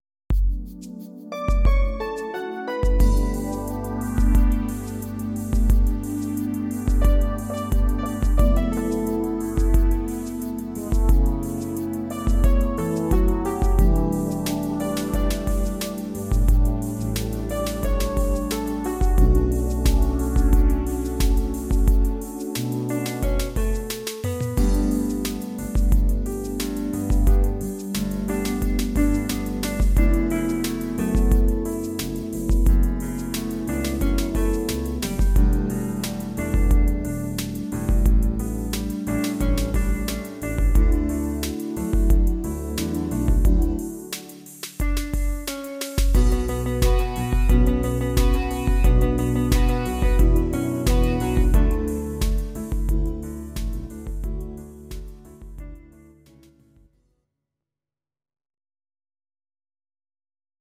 These are MP3 versions of our MIDI file catalogue.
Please note: no vocals and no karaoke included.
Your-Mix: Medleys (1041)